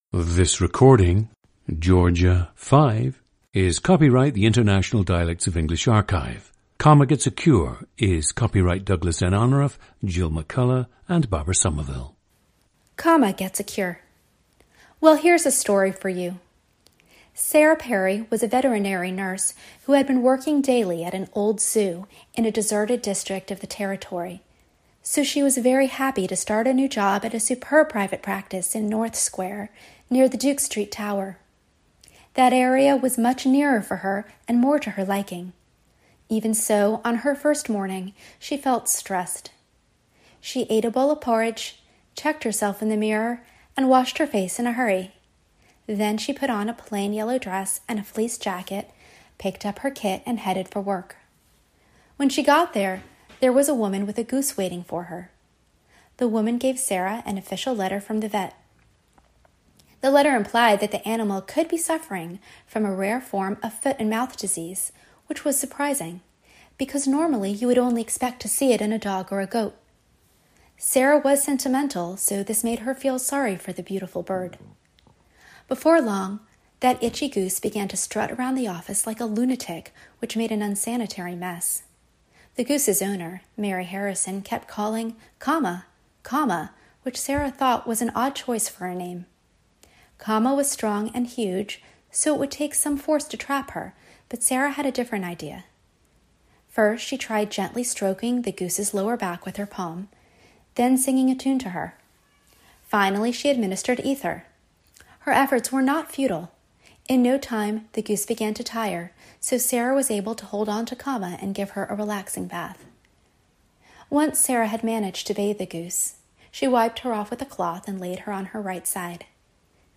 Listen to Georgia 5, a woman in her 40s from Atlanta, Georgia, United States.
GENDER: female
The recordings average four minutes in length and feature both the reading of one of two standard passages, and some unscripted speech.